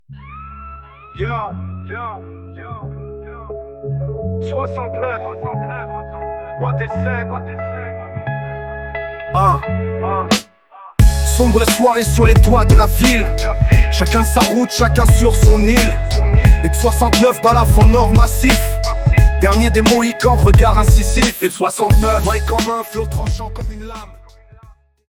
Style : Rap